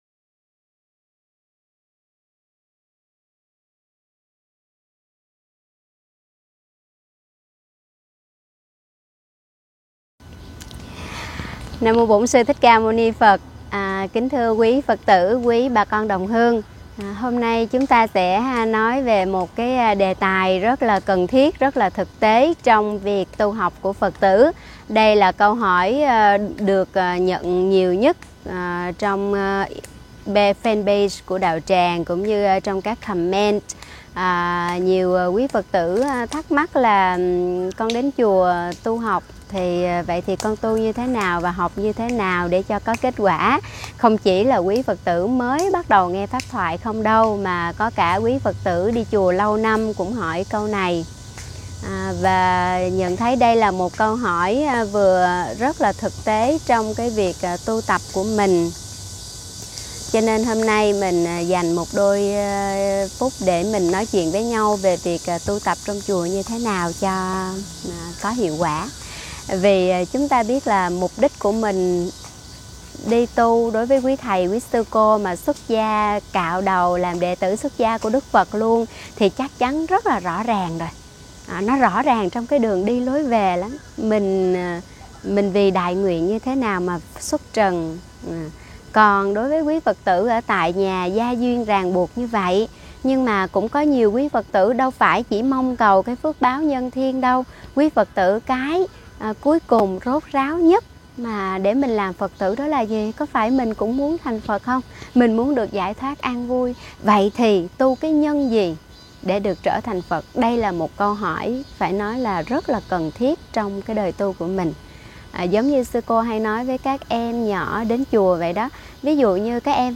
Thuyết pháp Tu phước đức - Tu trí tuệ